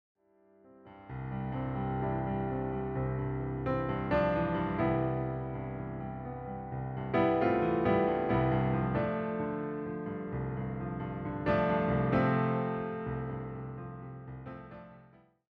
all performed as solo piano arrangements.